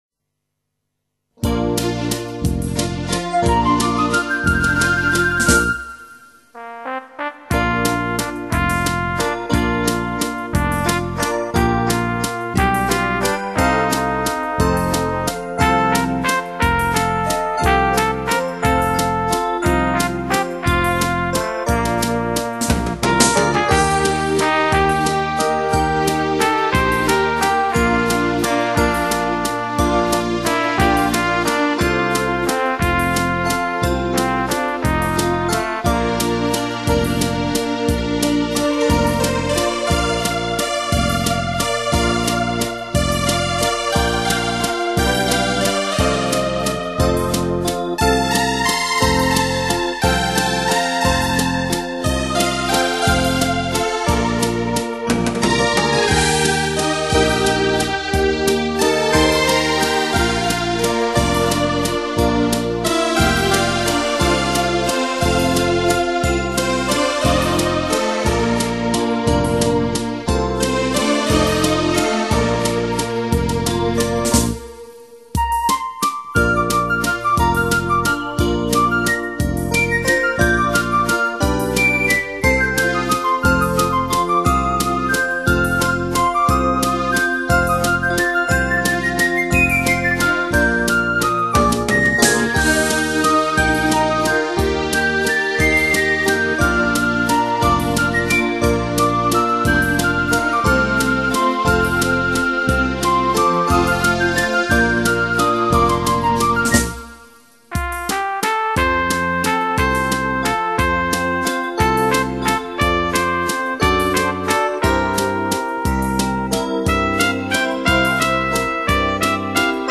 快三